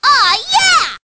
One of Princess Daisy's voice clips in Mario Kart DS